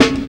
101 SNARE 4.wav